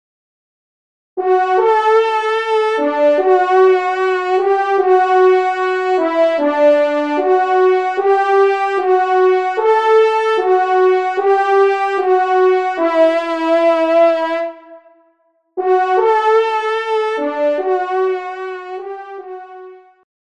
Genre : Fantaisie Liturgique pour quatre trompes
Pupitre 1° Trompe